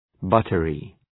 {‘bʌtərı}